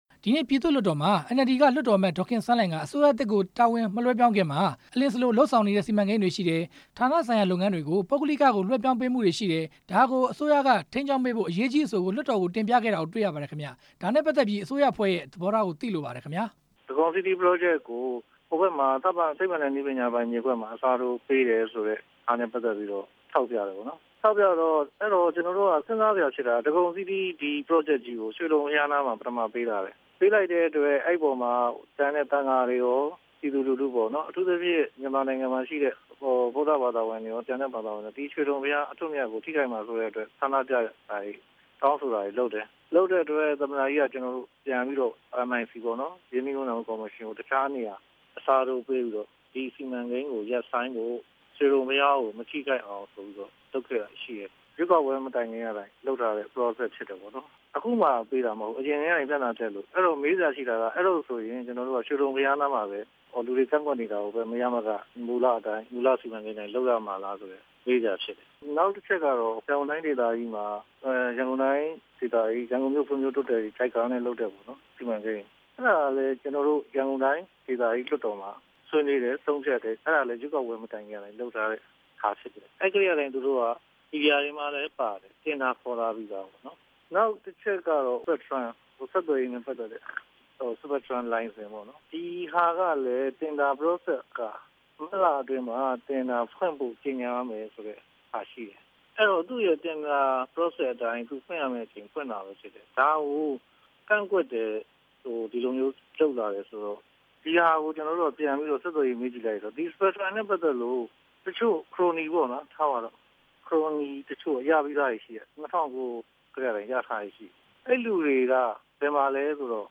အစိုးရစီမံကိန်း အရေးကြီးအဆိုအပေါ် ဦးဇော်ဌေးနဲ့ မေးမြန်းချက်